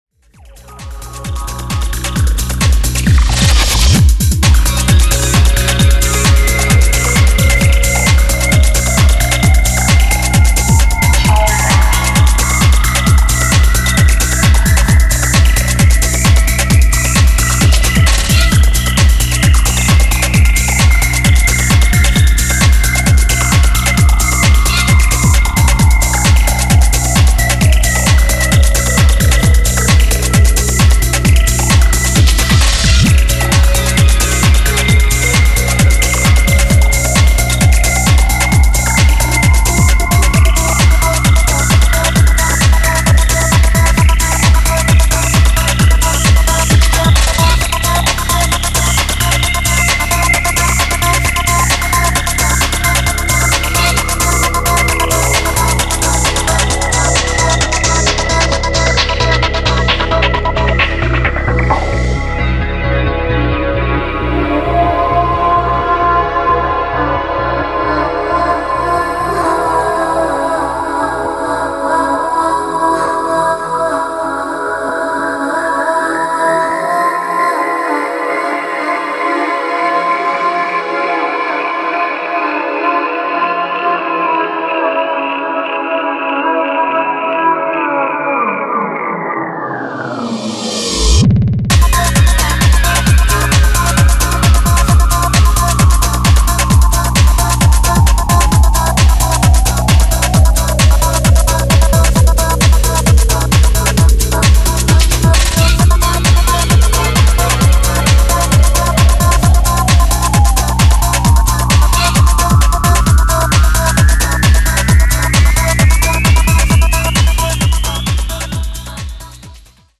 ブレイクビーツを伴って確かな重みを伝えるキックが図太いグルーヴを放つ